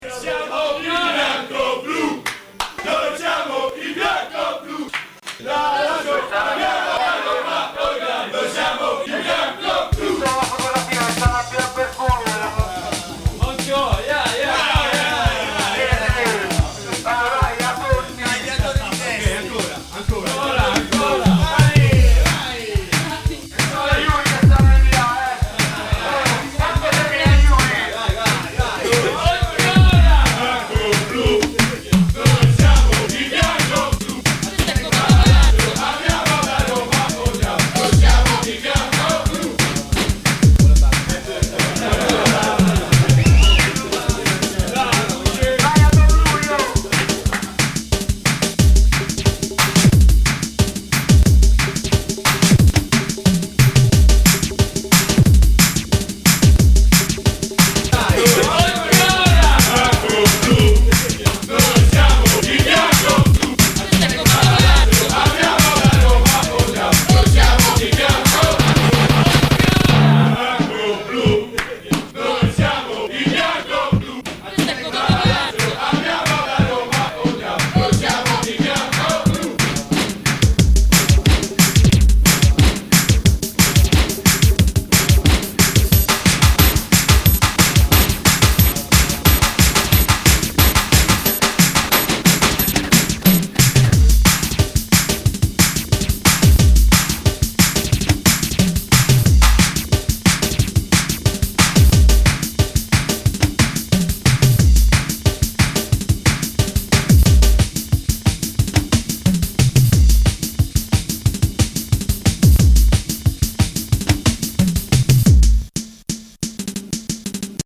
All the music was built with a sampler and keyboard from the stuff people sent in - with some of the ads mxed in there too.
There are Windows sounds all over it.
Italian football fans